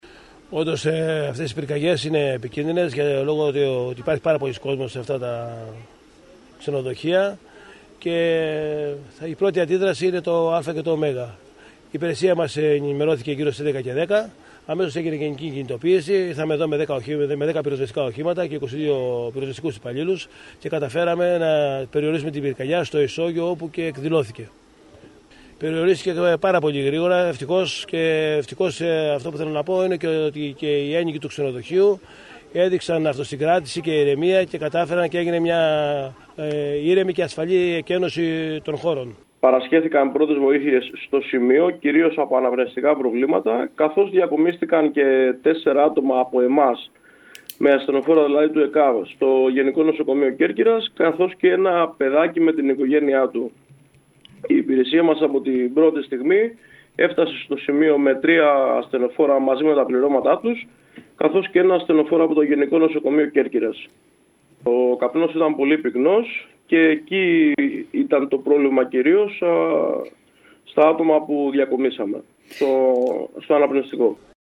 ρεπορτάζ